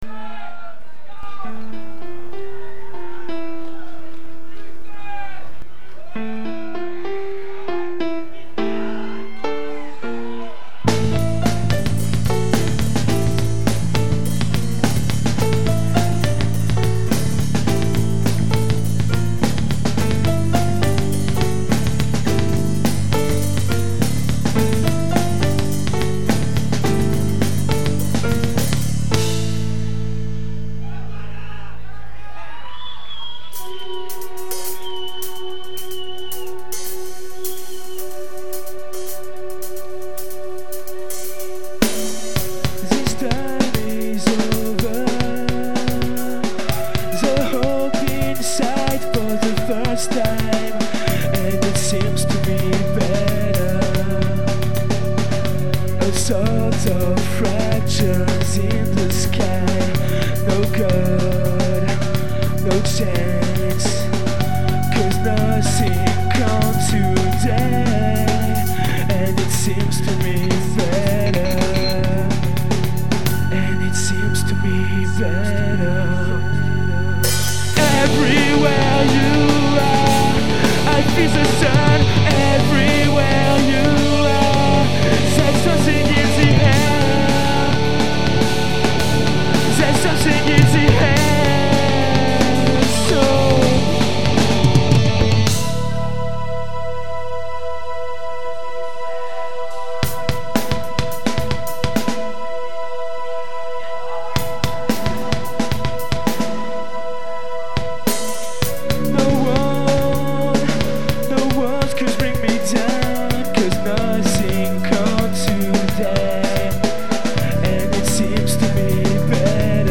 On joue ensemble depuis moins d'un an, On est 6 agés de 16 à 18ans, un chanteur, un guitariste, une pianiste, un batteur, un homme à tout faire ( frontman, backliner, 2eme chanteur, flute, banjo ) et moi à la basse.
C'est un enregistrement en live de notre 2eme concert samedi dernier qui s'est plutôt bien passé. Il y a pas mal d'erreurs mais on va mettre ca sur le compte de l'emotion ( 300 personnes ).
Ben ......... y'avait de l'ambiance !!!!!
Je pense que sur le premier couplet, la batterie devrait être moins fournie, et garder le 2ème comme ça, ça gagnerait en "surprise" mais surtout en progression.
Il chante souvent faux, bref pas terrible du tout.
Bon son de basse, bon son du groupe, c'est enregistré comment?